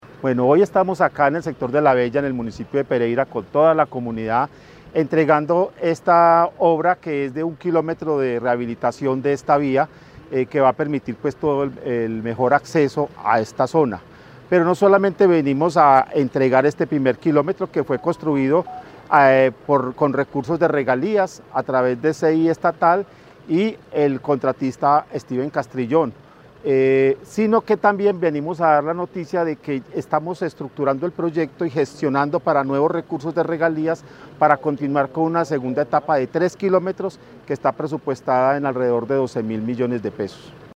ESCUCHAR AUDIO JORGE HERNANDO COTE ANTE -EC INFRAESTRUCTURA RDA